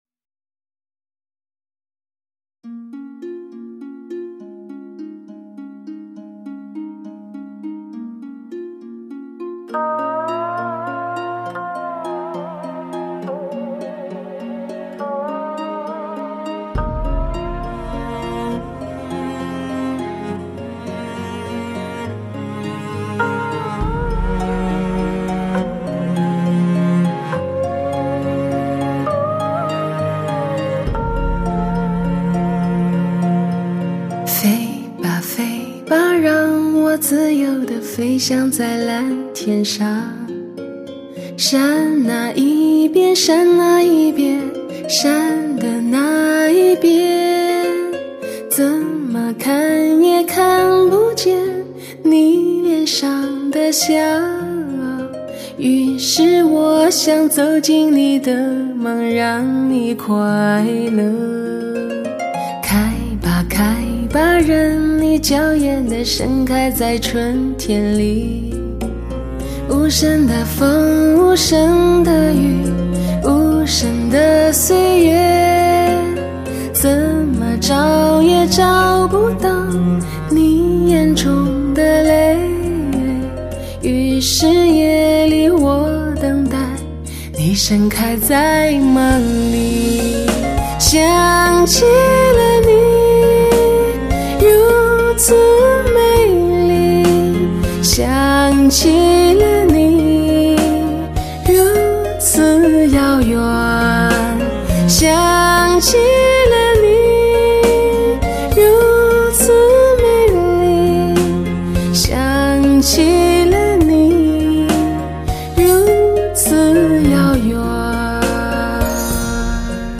唱片类型：华语流行